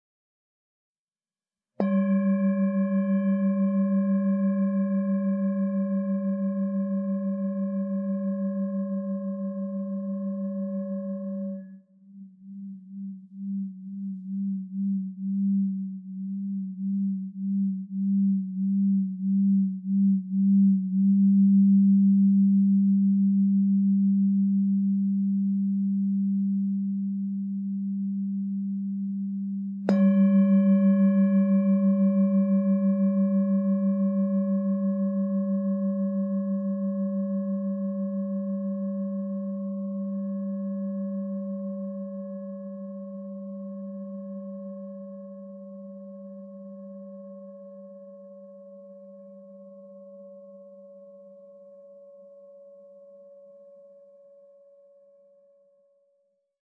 Meinl Sonic Energy Synthesis Series Singing Bowl - Venus Flower - 1000g (SB-S-VF-1000)